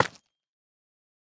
Minecraft Version Minecraft Version latest Latest Release | Latest Snapshot latest / assets / minecraft / sounds / block / calcite / break2.ogg Compare With Compare With Latest Release | Latest Snapshot
break2.ogg